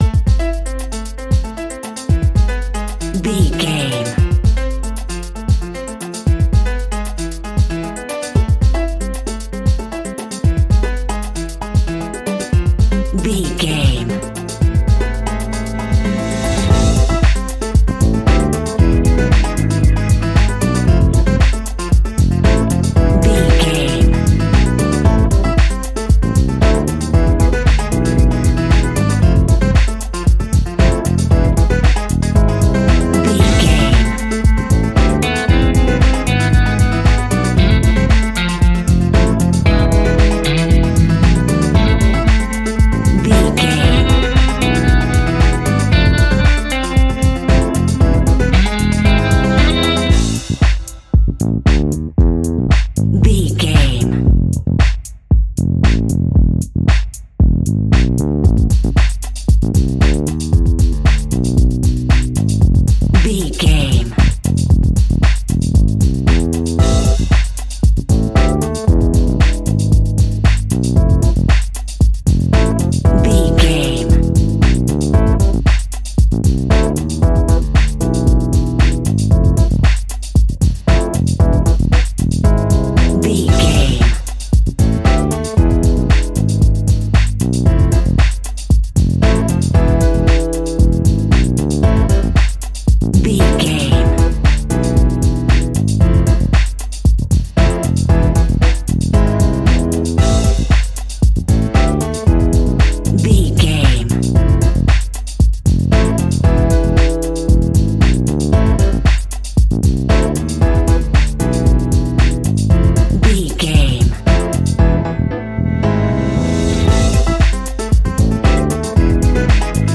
Aeolian/Minor
World Music
drums
bass guitar
brass
saxophone
trumpet
fender rhodes
clavinet